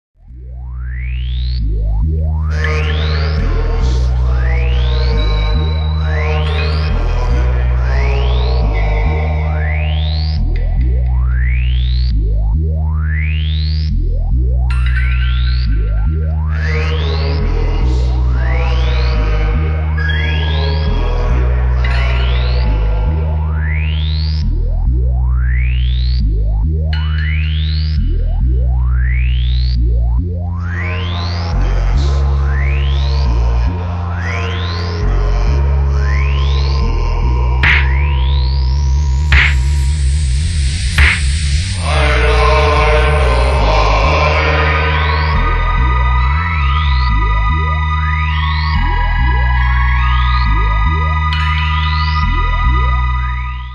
legendární pražská alternativní skupina